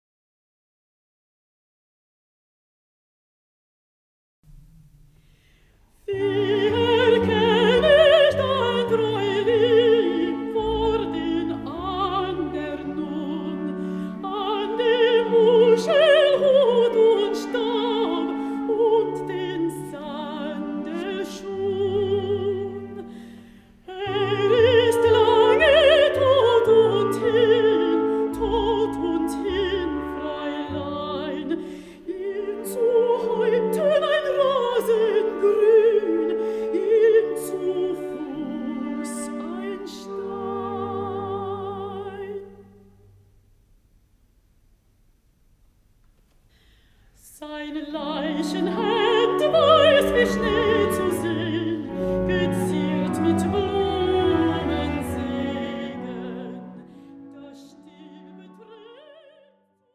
Voicing: Voice